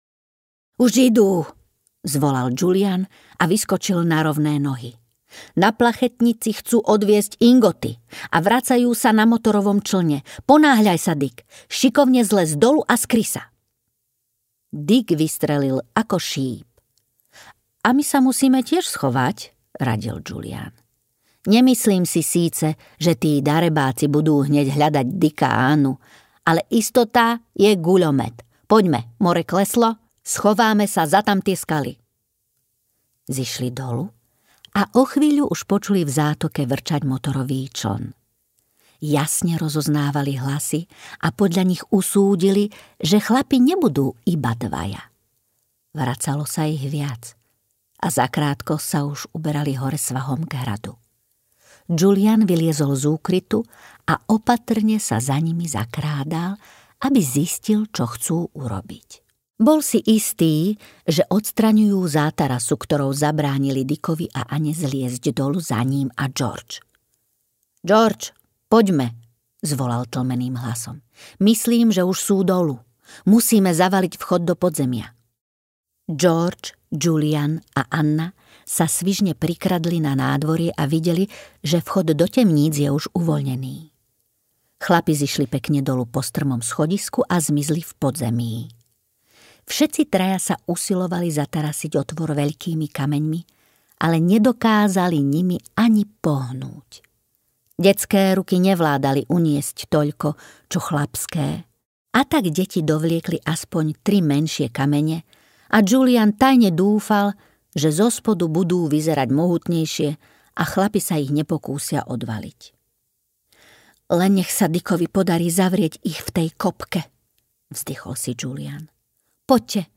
Slávna 5 na ostrove pokladov audiokniha
Ukázka z knihy